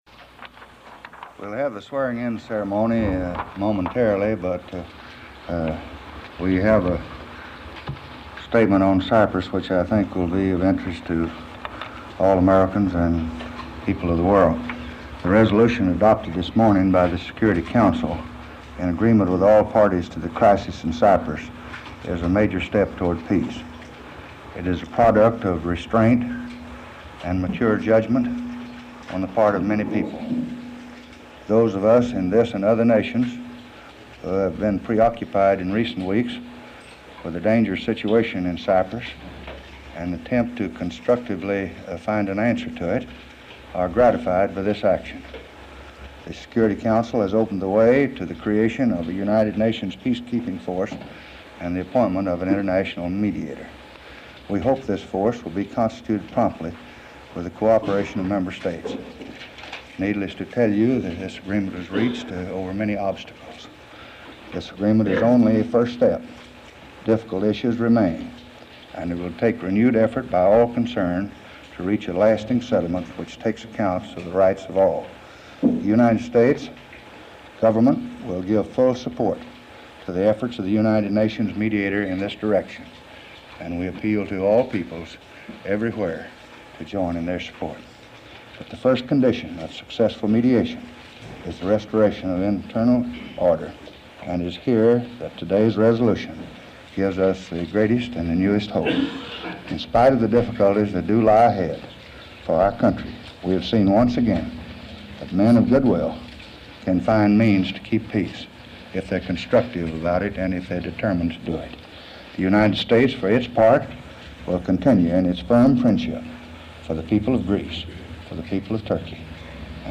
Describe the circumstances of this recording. Security Council Cyprus Crisis (Cyprus : 1963) Peace-building Cyprus Material Type Sound recordings Language English Extent 00:02:23 Venue Note Broadcast on Voice of America, Mar. 4, 1964.